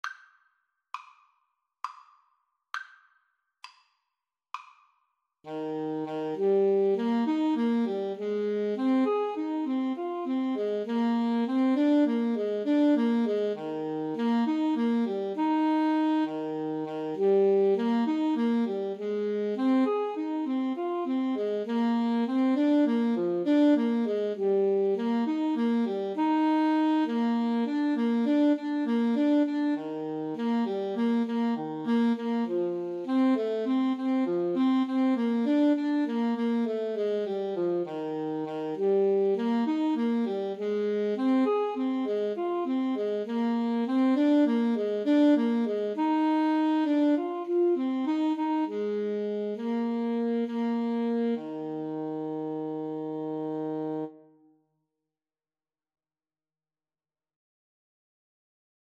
9/8 (View more 9/8 Music)
Moderato